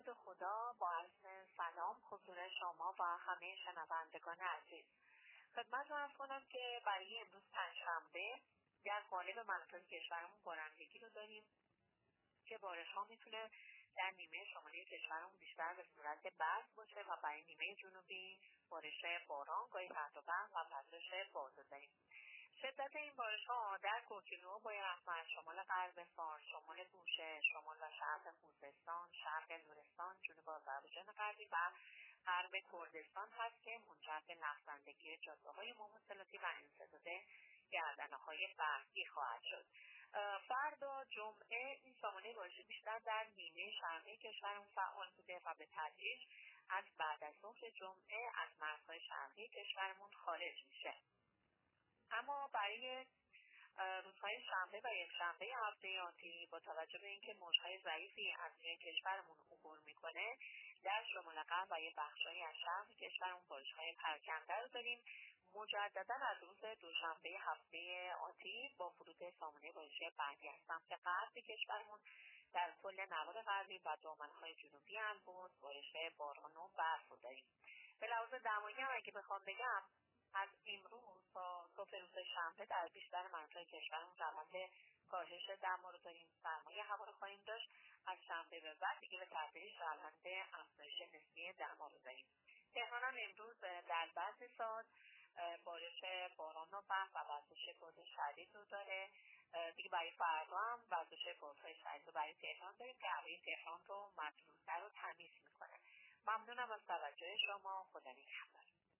گزارش آخرین وضعیت جوی کشور را از رادیو اینترنتی پایگاه خبری وزارت راه و شهرسازی بشنوید.
گزارش رادیو اینترنتی از آخرین وضعیت آب و هوای نهم بهمن؛